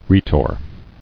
[rhe·tor]